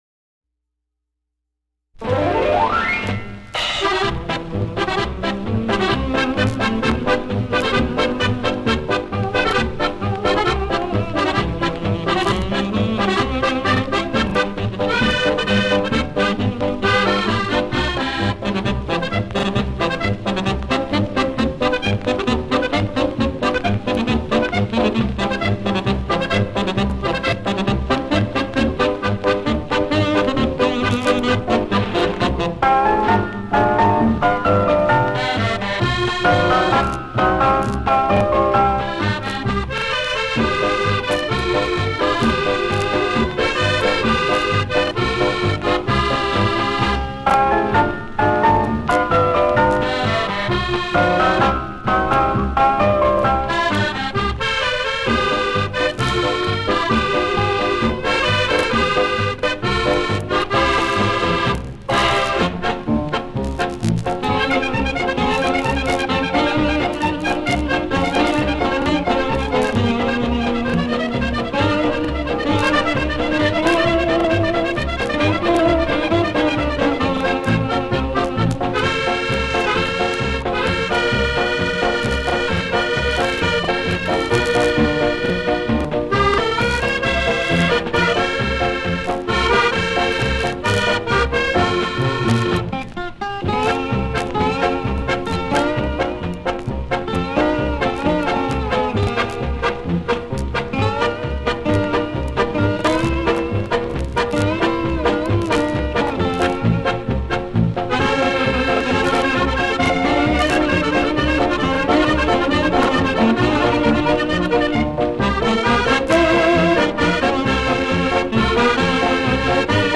Polka de antaño